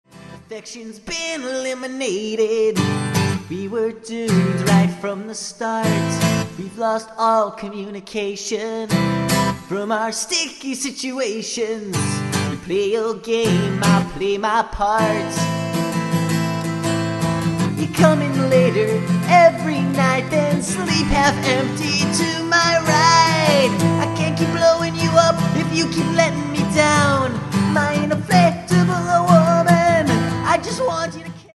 Guitar and Vocals
innocent vocal quality and folk-like guitar accompaniment
and a good sense of melody.